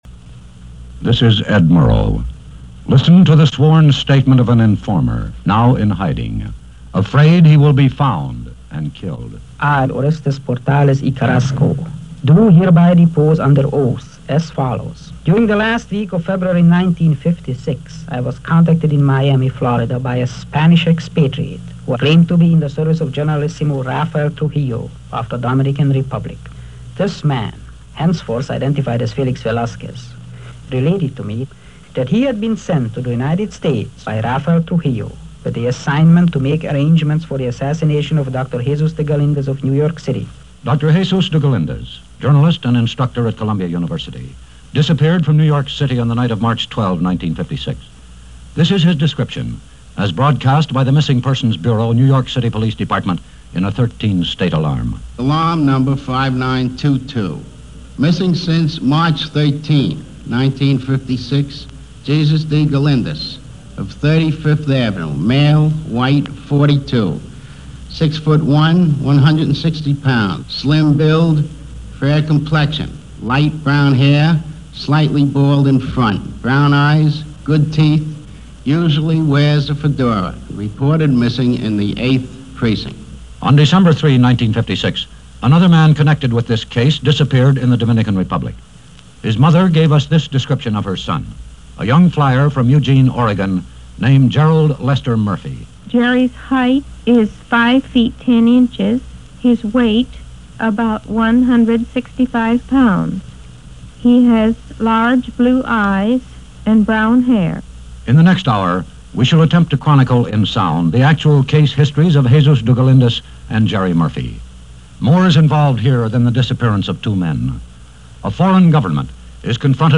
Dr. Galindez: A Case Of Intrigue - The Scent Of Terrorism - 1957 - Past Daily After Hours Reference Room - CBS Radio Reports - May 20, 1957